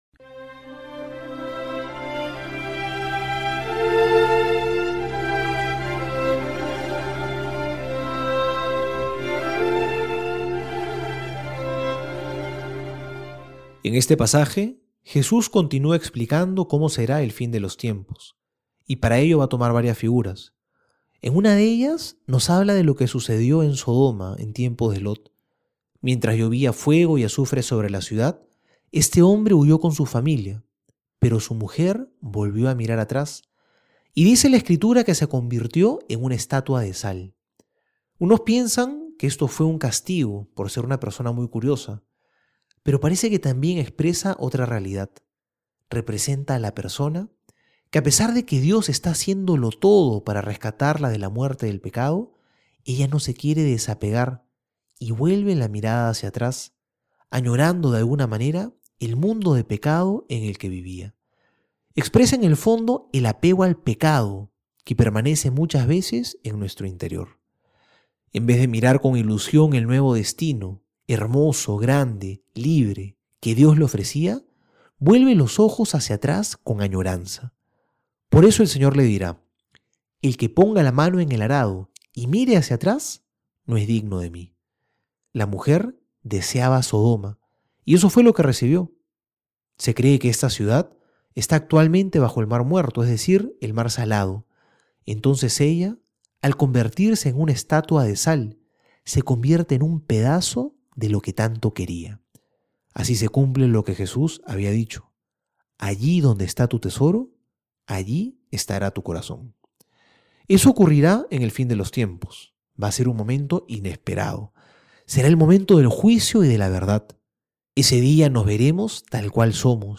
Homilía para hoy: